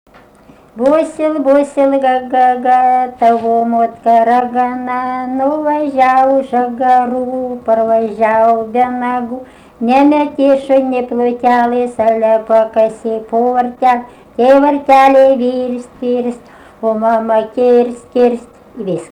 daina, vaikų